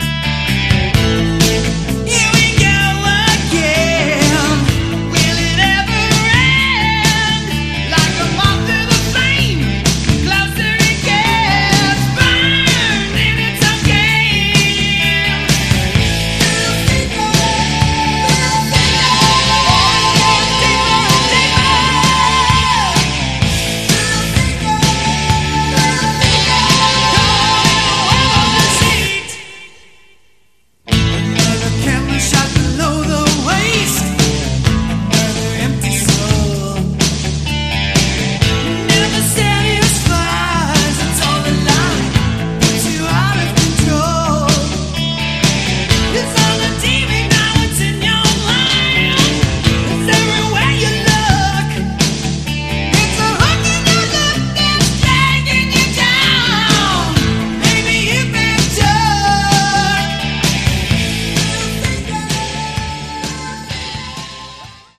Category: Christian Melodic Metal
lead guitar
drums
rhythm guitar, vocals